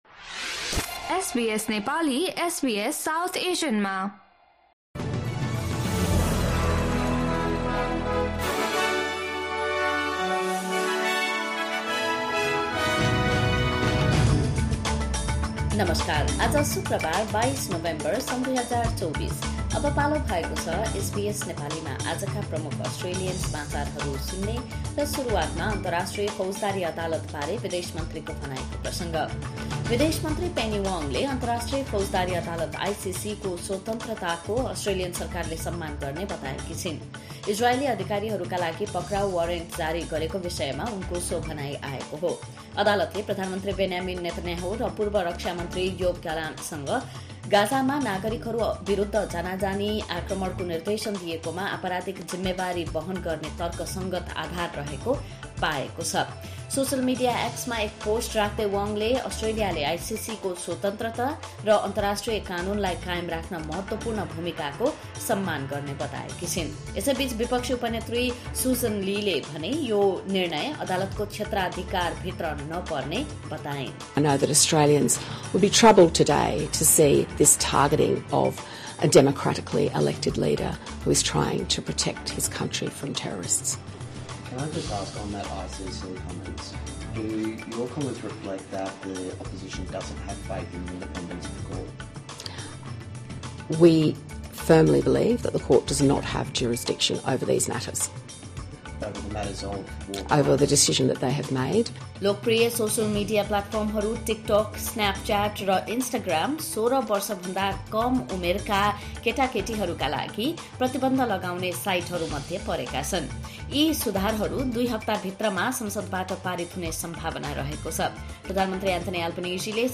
SBS Nepali Australian News Headlines: Friday, 22 November 2024